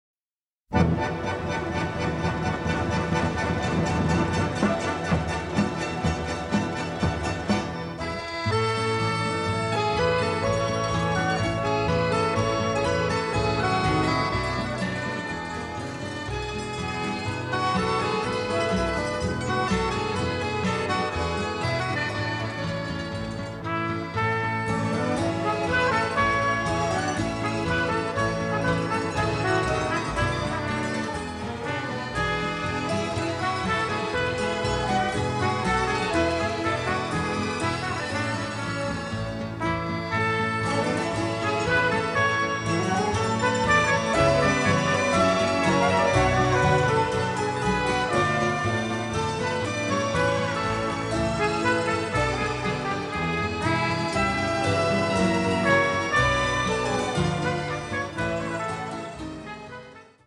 a colorful, bittersweet score
The score also features a bossa, a waltz and a tango.